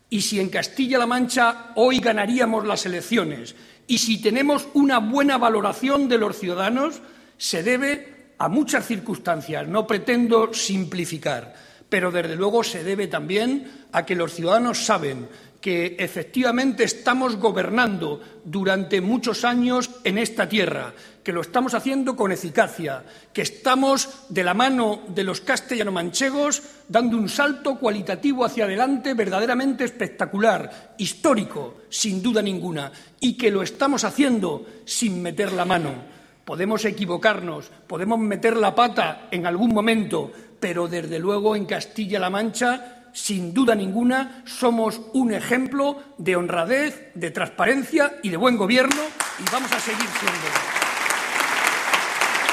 Barreda hizo estas declaraciones durante su intervención en el Comité regional del PSCM-PSOE, en Toledo.
Corte sonoro del Comité Regional